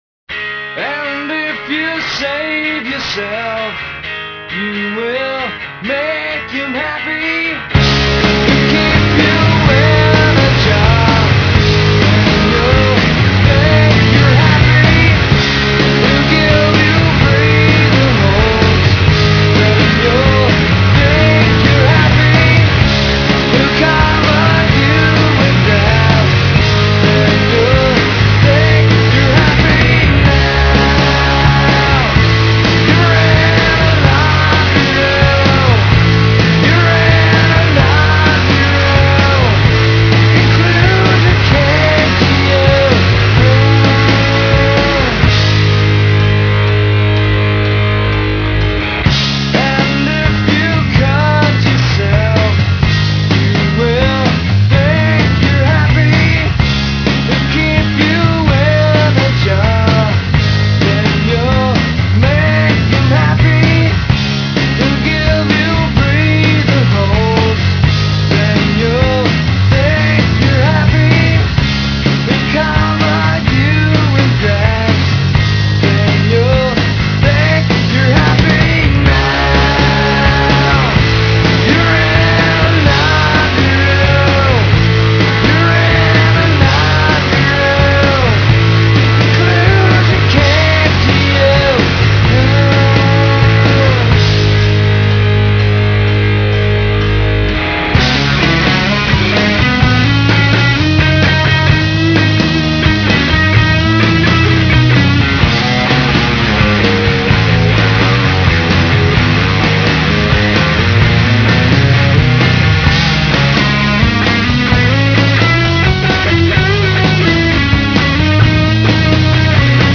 STUDIO SOUND CLIPS